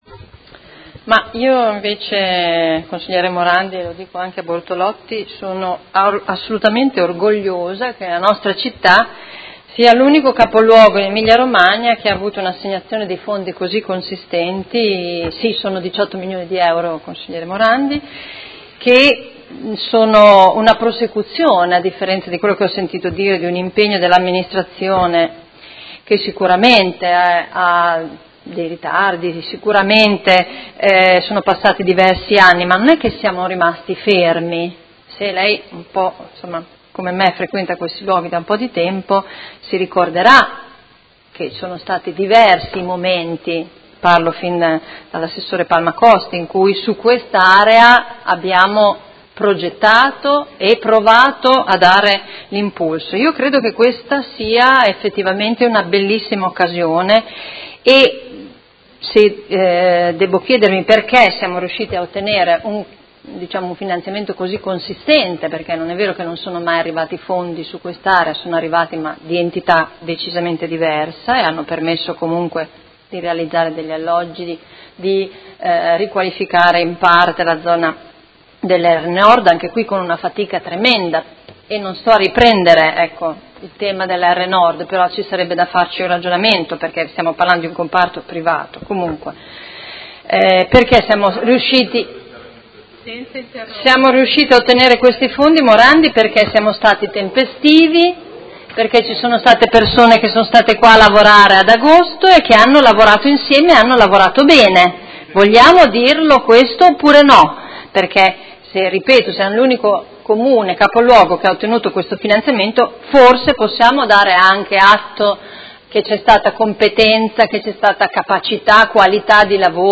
Seduta del 25/05/2017 Dibattito su Delibera Linee di indirizzo per il riordino funzionale e morfologico dell’ Area urbana a Nord di Modena “Fascia ferroviaria” – Approvazione Masterplan e su Ordine del giorno 80690 avente per oggetto: Progetto Periferie, rigenerazione e innovazione.